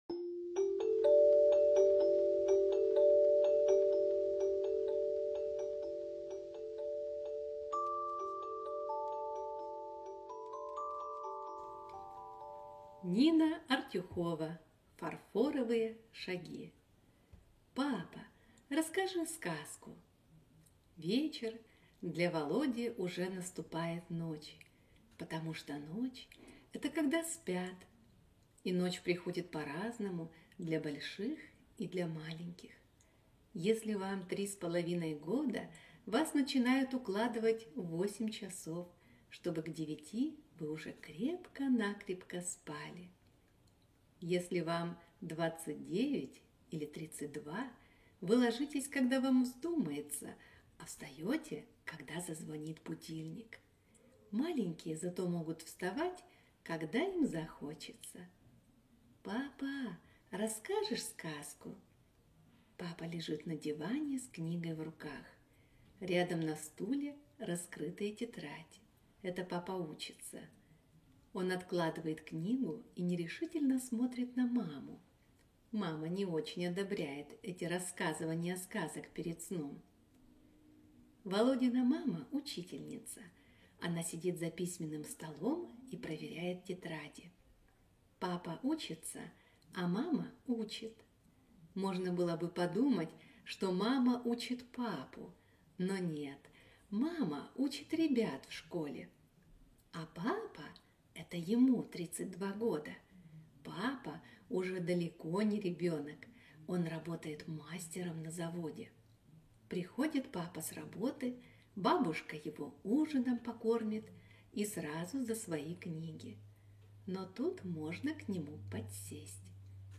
Фарфоровые шаги - аудио рассказ Артюховой - слушать онлайн